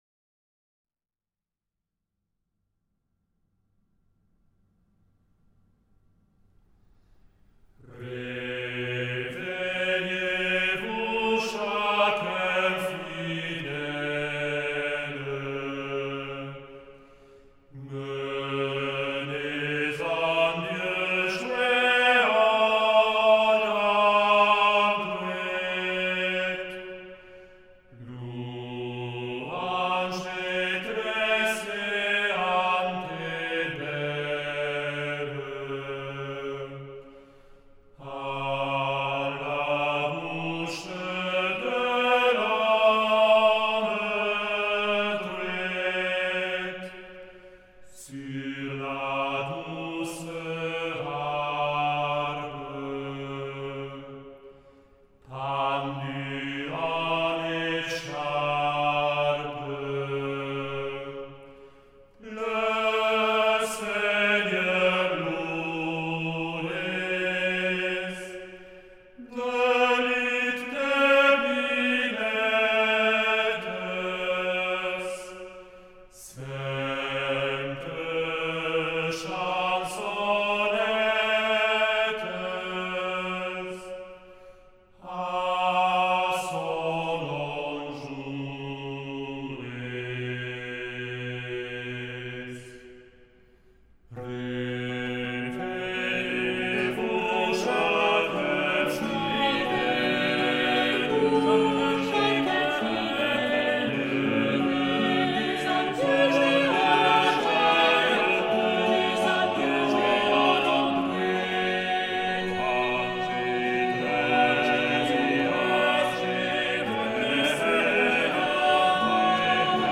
Musique d’entrée